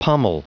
Prononciation du mot pommel en anglais (fichier audio)
Prononciation du mot : pommel